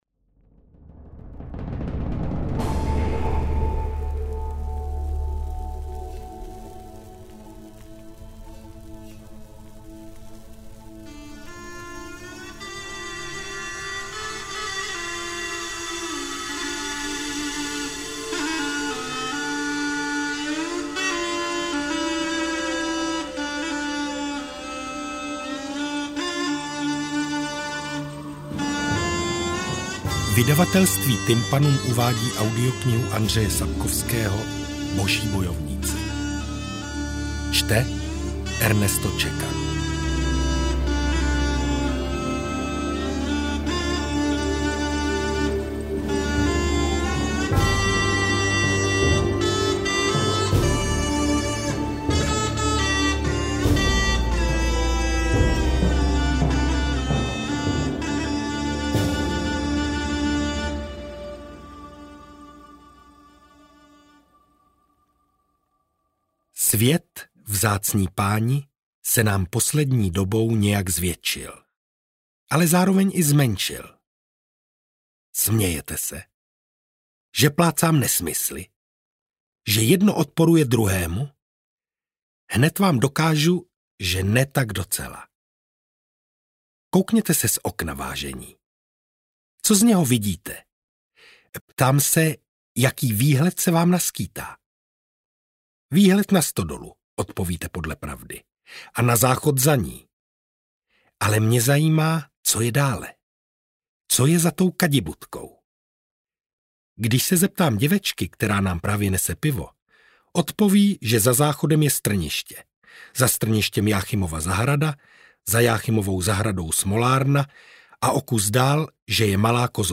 AudioKniha ke stažení, 73 x mp3, délka 26 hod. 47 min., velikost 1501,9 MB, česky